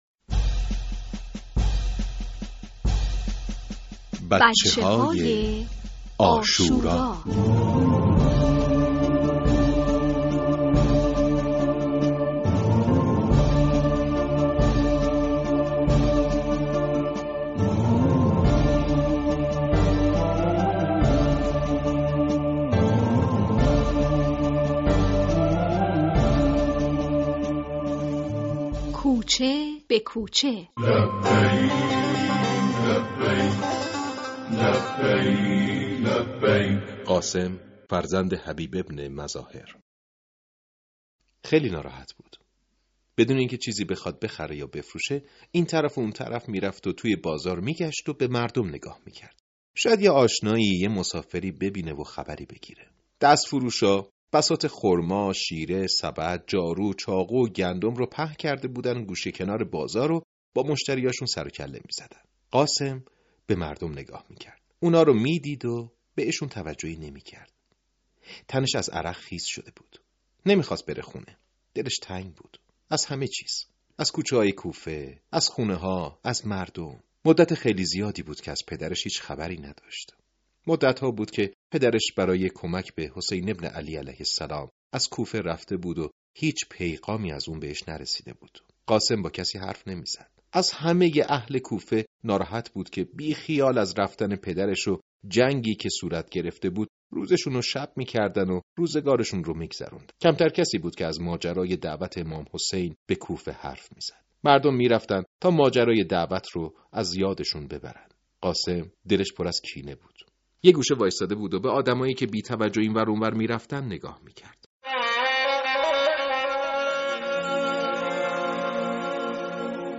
# بچه های عاشورا # ماه محرم الحرام # قصه # کتاب صوتی # پادکست